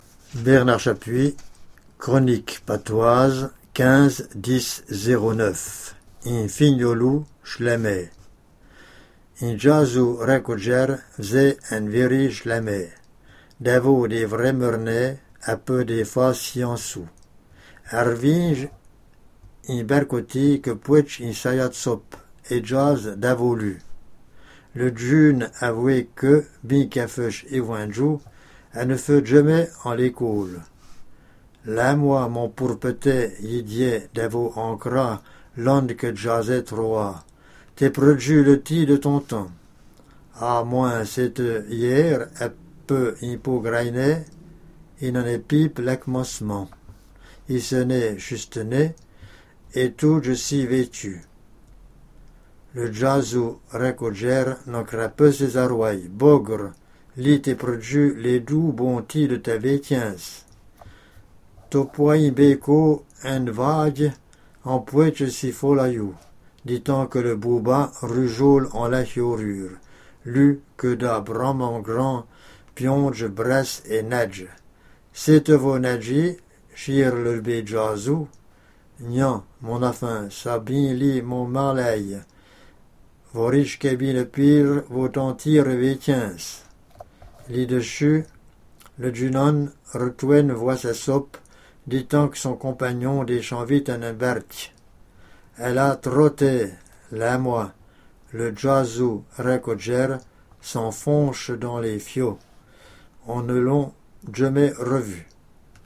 Patois Jurassien